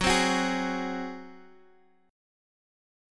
Gbsus2b5 chord